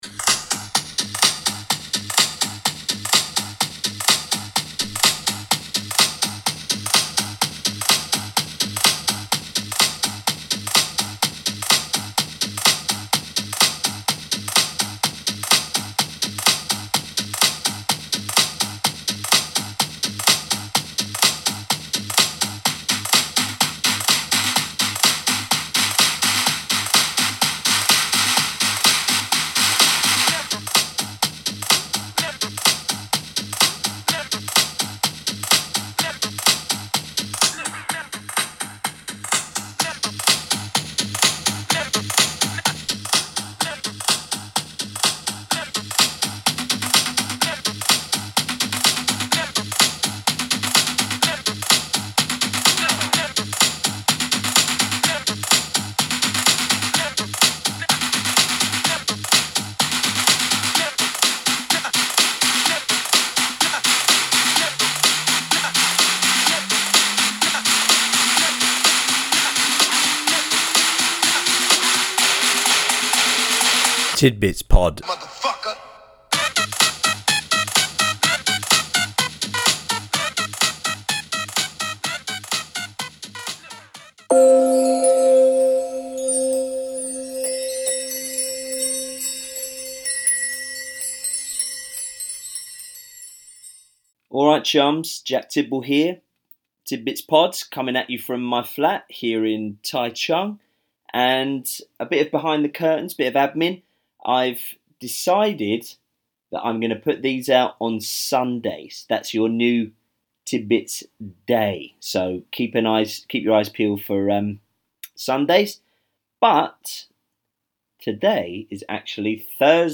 Recorded at home in Taichung, Taiwan.